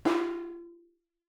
timpsnare_mf.wav